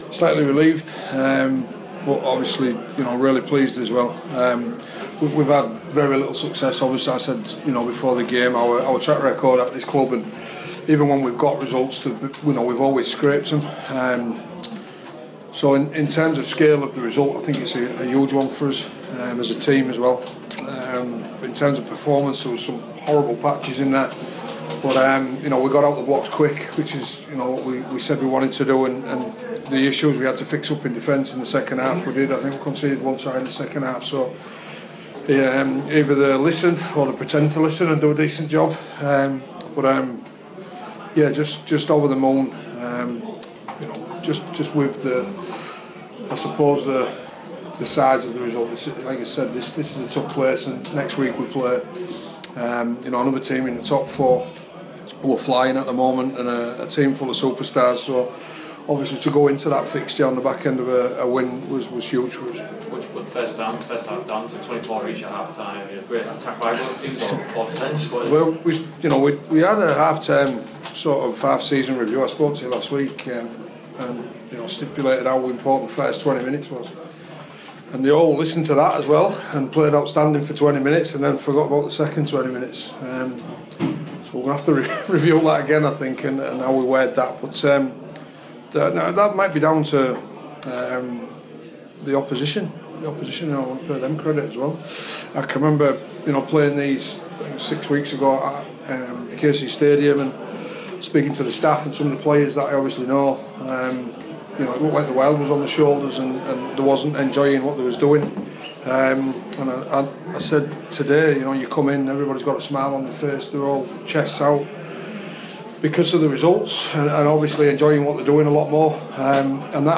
Hull FC boss Lee Radford speaks to the media after his side's 46-28 win over Wakefield Trinity Wildcats